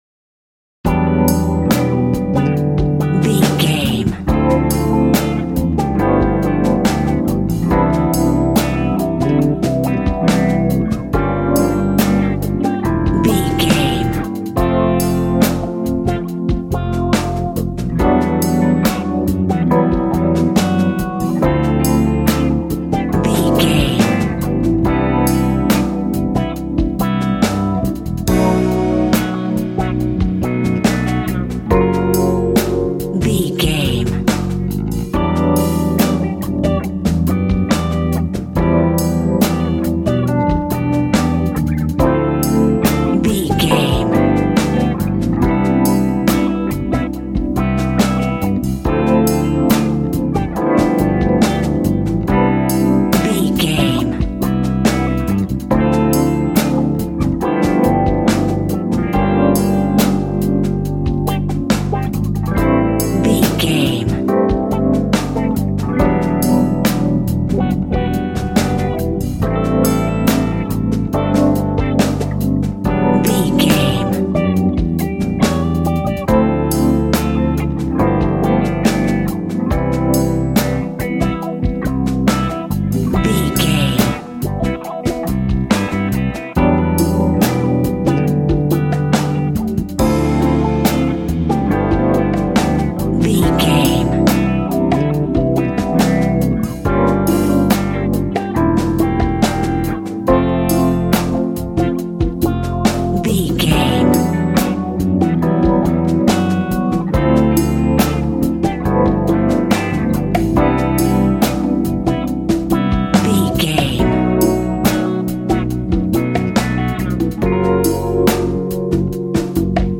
Ionian/Major
instrumentals